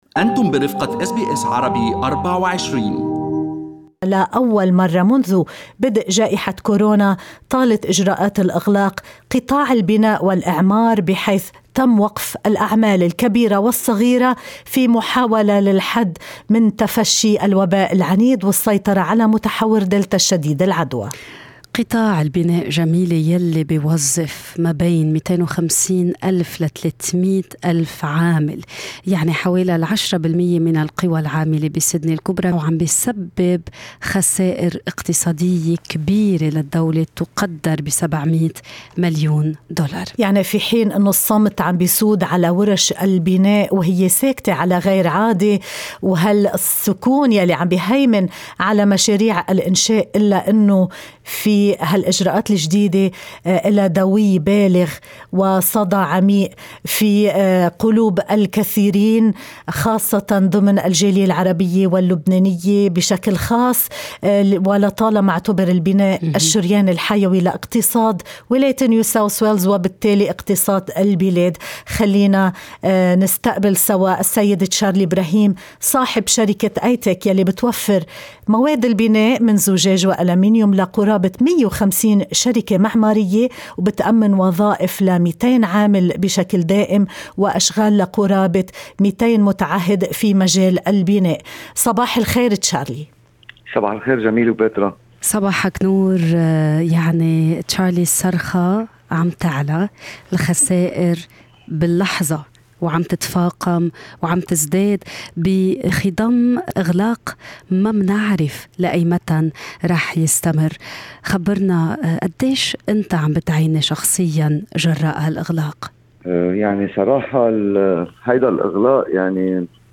هذا الحوار